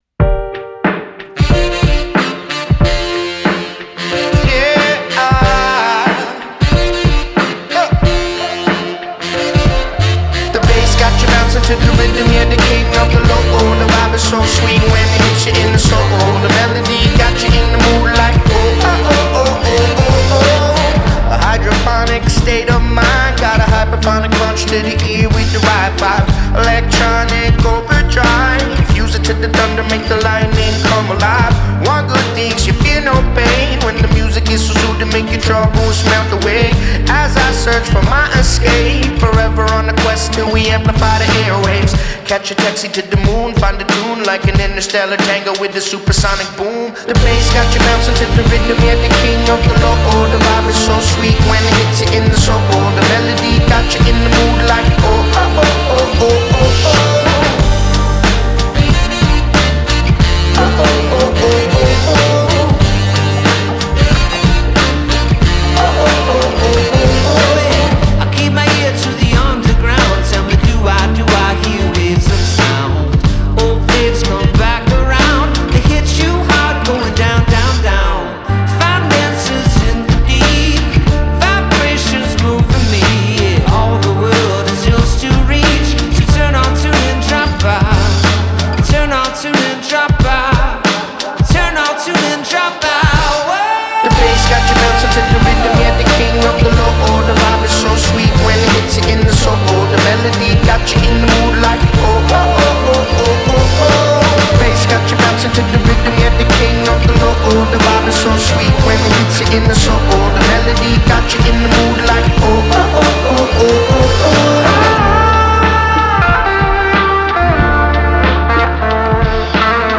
singer/guitarist
alternative / indie rock single